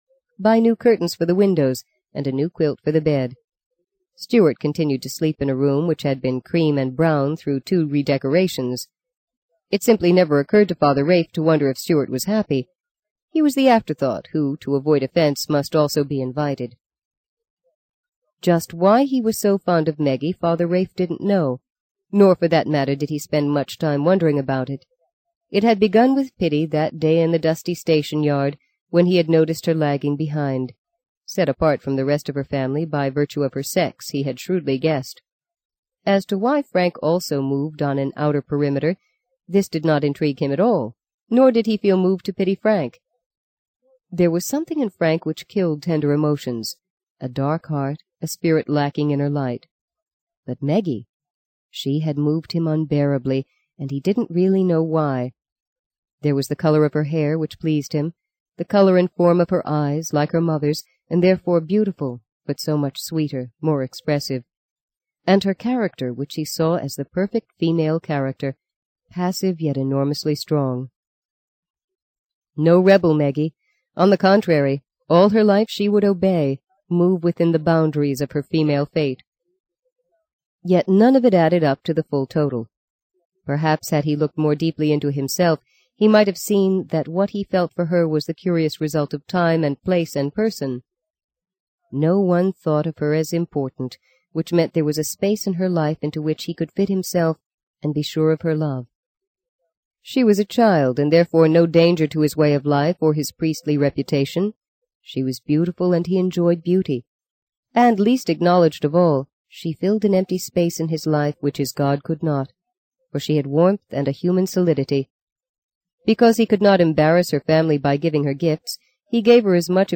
在线英语听力室【荆棘鸟】第四章 15的听力文件下载,荆棘鸟—双语有声读物—听力教程—英语听力—在线英语听力室